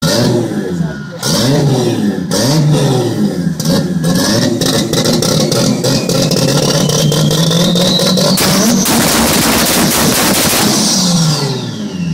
anti-lag-supra.mp3